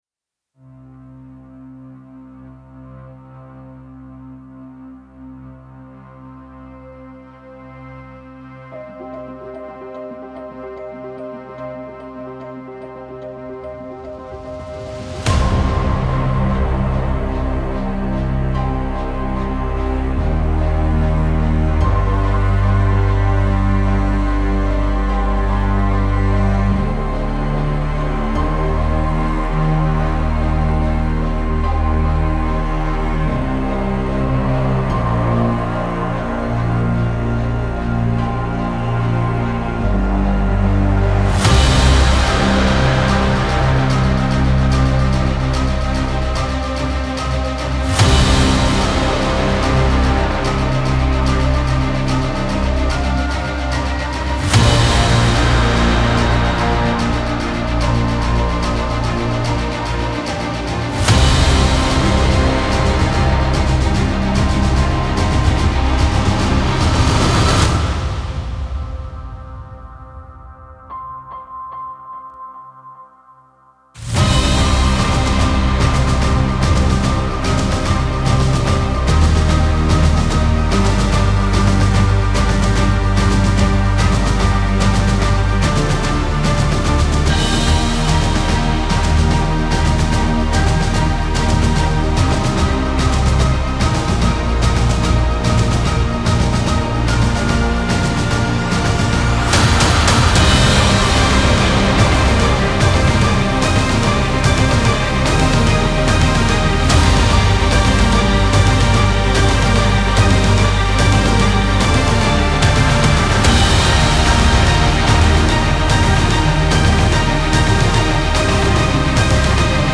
سبک ارکسترال , موسیقی بی کلام